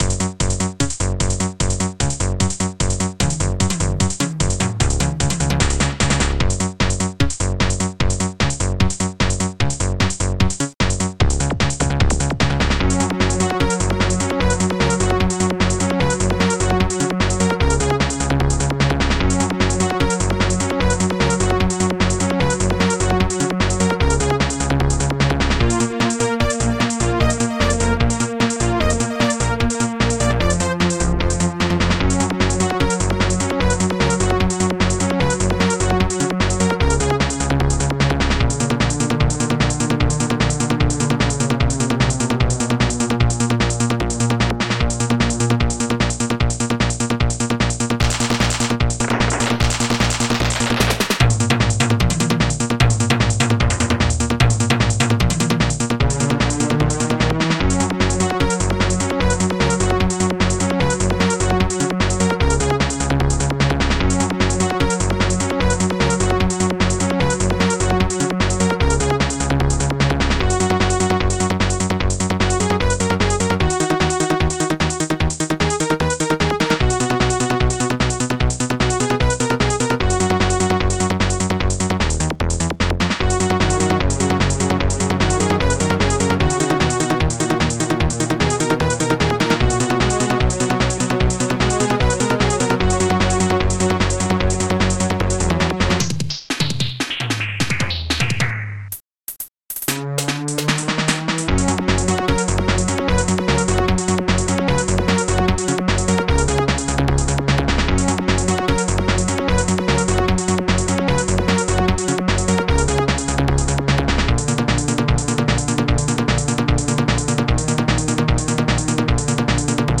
SoundTracker Module  |  1990-07-31  |  95KB  |  2 channels  |  44,100 sample rate  |  3 minutes, 43 seconds
Protracker and family
st-03:bass12
st-03:bassdrum6
st-03:snare19
st-03:synbrass
st-03:panflute